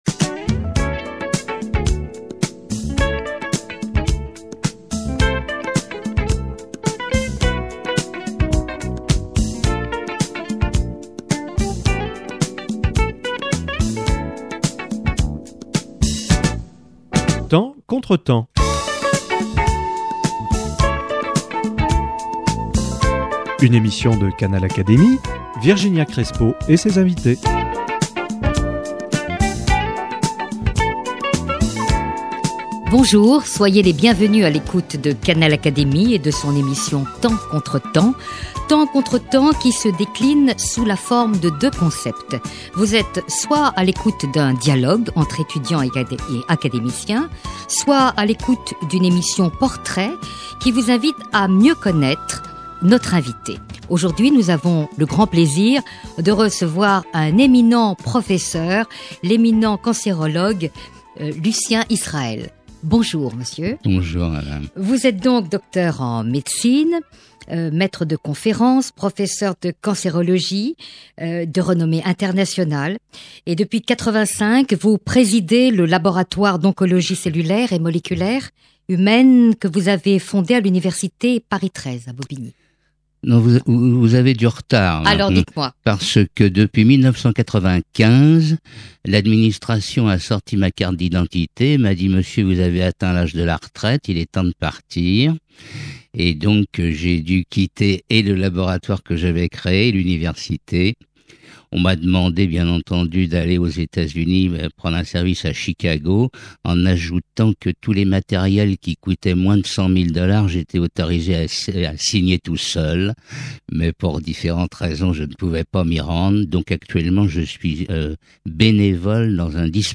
Rencontre avec Lucien Israël
Le célèbre cancérologue de réputation internationale, Lucien Israël, évoque pour nous son enfance, sa passion pour la médecine, pour la poésie, pour le mystère et la merveilleuse complexité du corps humain. Il nous confie ses révoltes et ses espoirs.